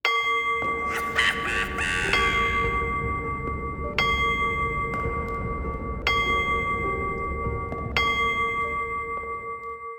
cuckoo-clock-05.wav